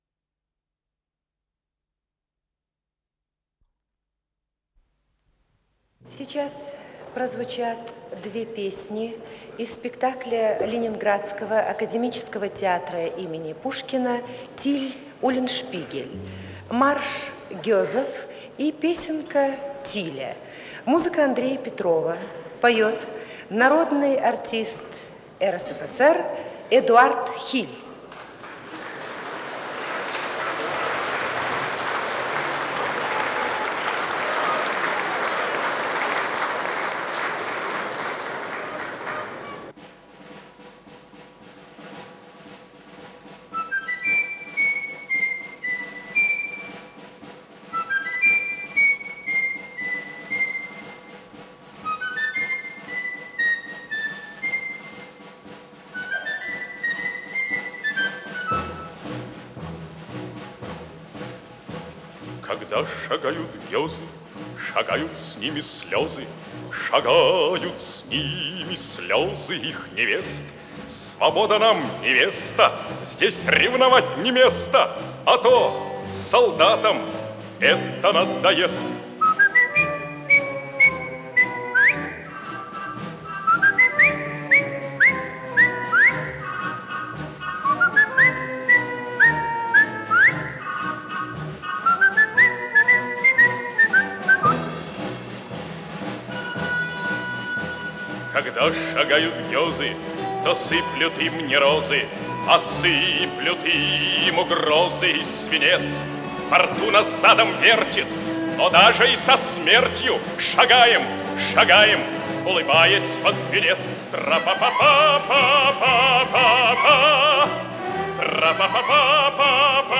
пение
Запись из Колонного зала Дома союзов от 17 ноября 1974 года